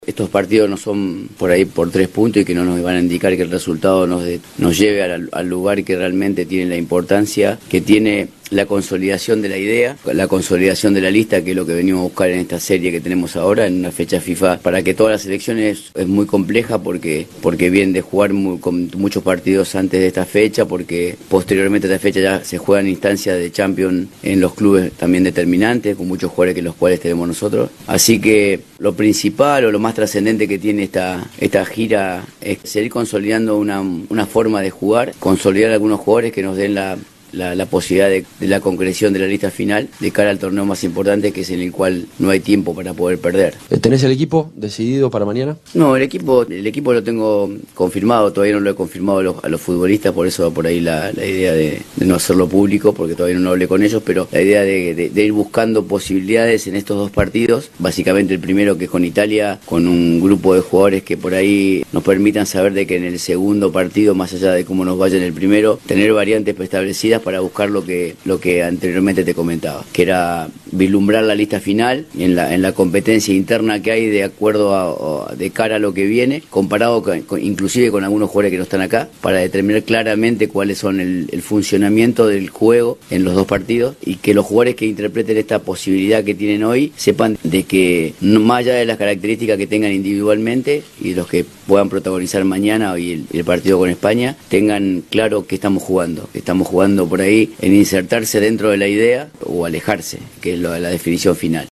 En conferencia de prensa, el técnico argentino no dio la formación titular para jugar mañana a las 16:45 ante Italia en Manchester.
Escuchá al técnico argentino.